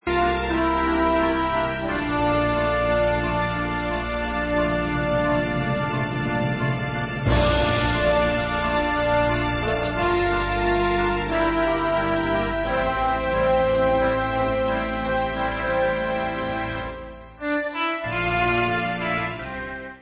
1996 studio album w.
Pop/Symphonic